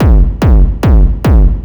Kick 145-BPM.wav